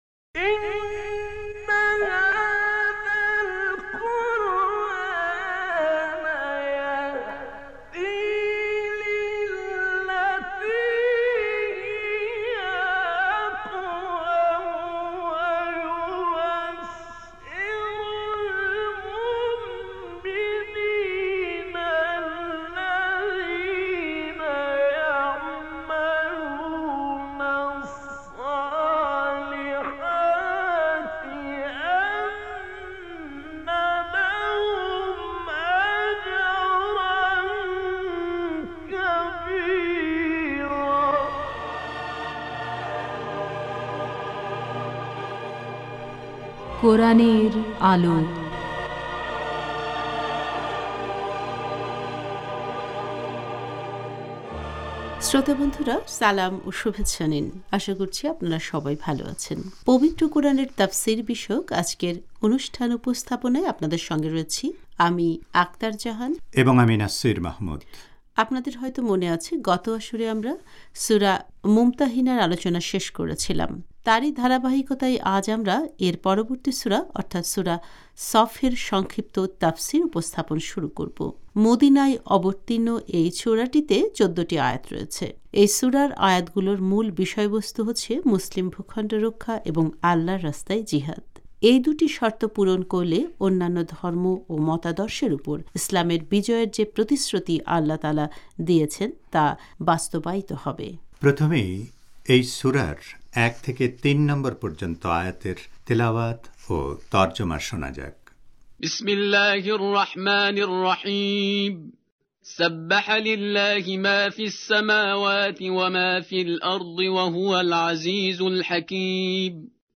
প্রথমেই এই সূরার ১ থেকে ৩ নম্বর পর্যন্ত আয়াতের তেলাওয়াত ও তর্জমা শোনা যাক: